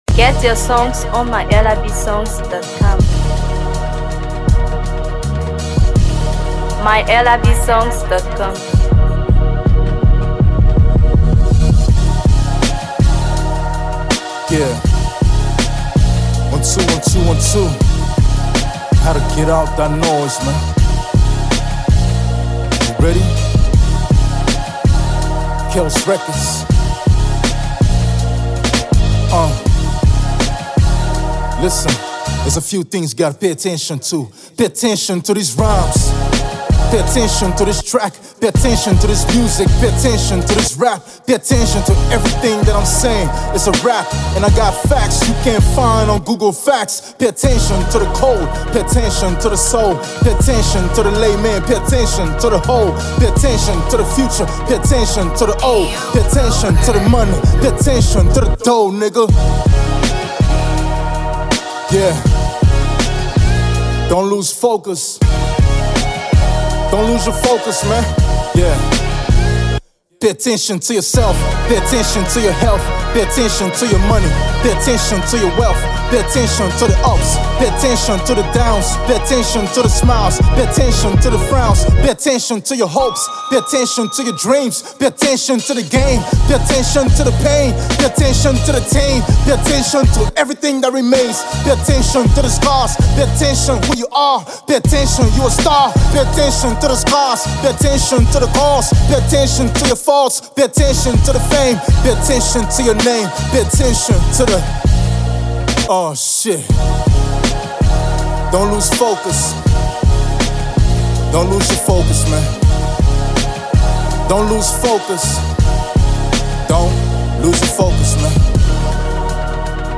Hip HopMusic